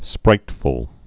(sprītfəl)